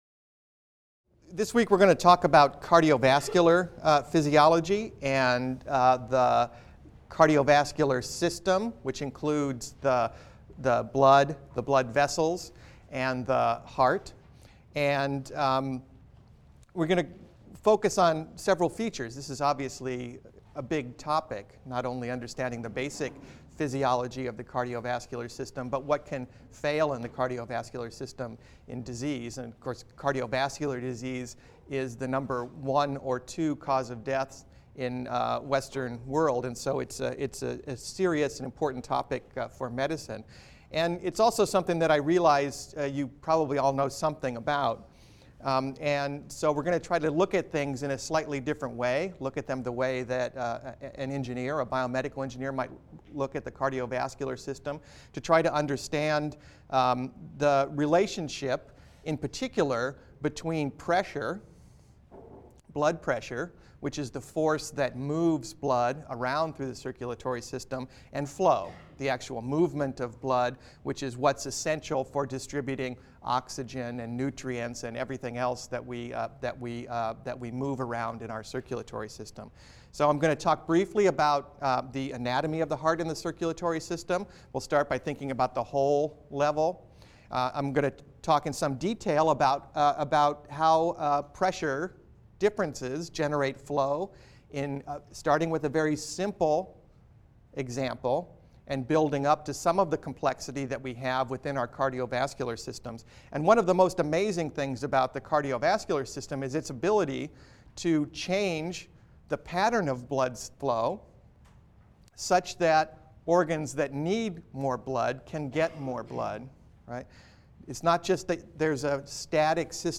BENG 100 - Lecture 13 - Cardiovascular Physiology | Open Yale Courses